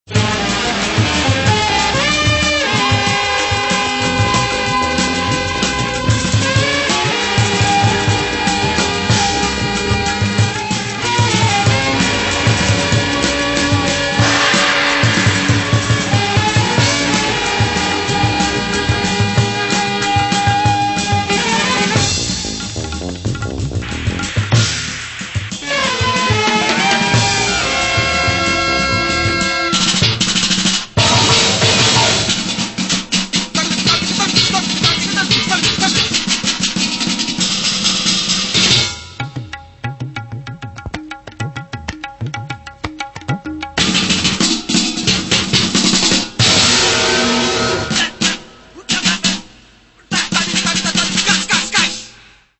Recorded in August and September 1981 in Hamburg